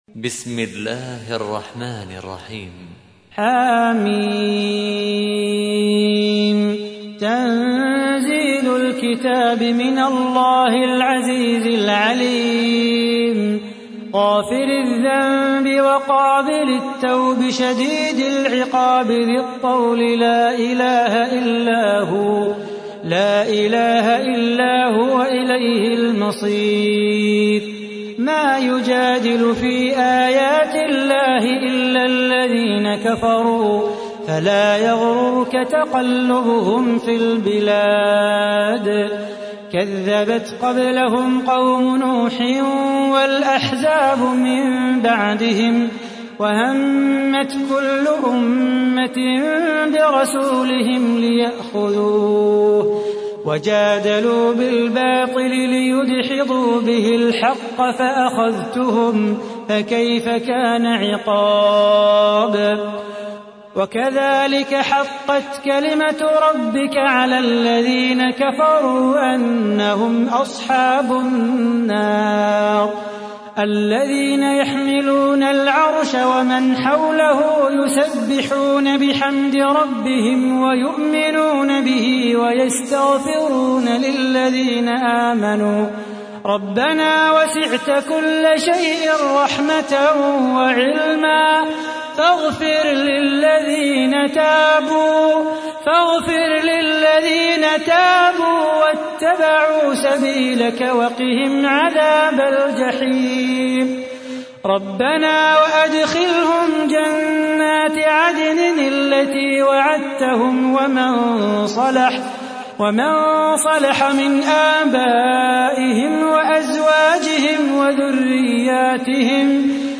تحميل : 40. سورة غافر / القارئ صلاح بو خاطر / القرآن الكريم / موقع يا حسين